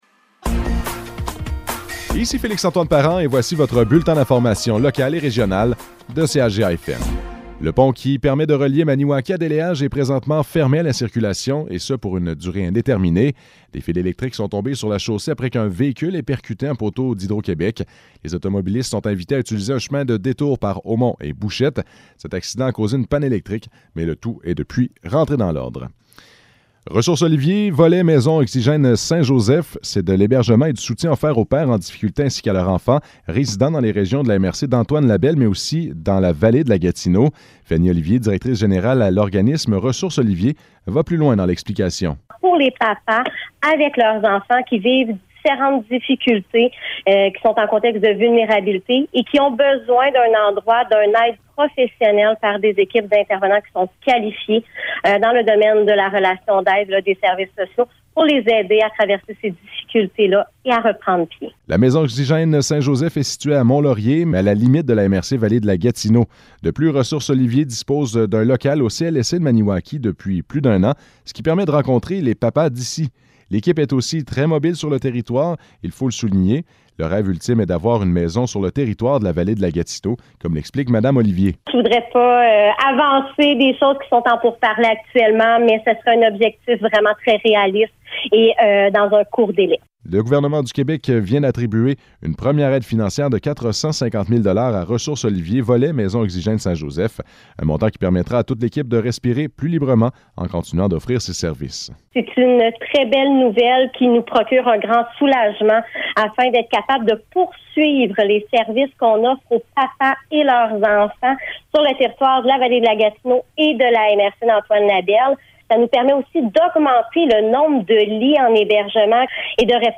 Nouvelles locales - 16 janvier 2023 - 12 h